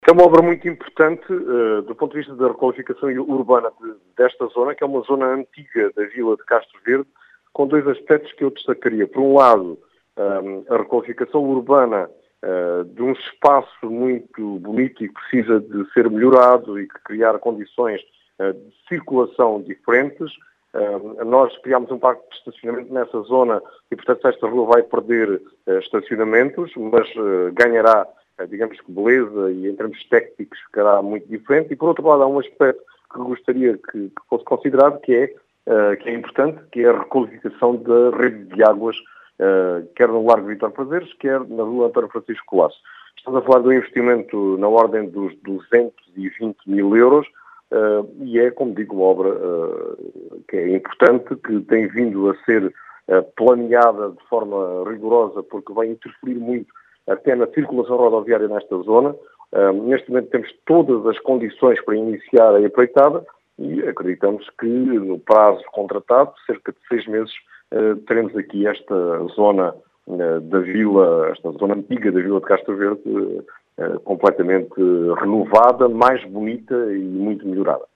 As explicações são de António José Brito, presidente da Câmara de Castro Verde, que diz tratar-se de uma “obra muito importante” para Castro Verde.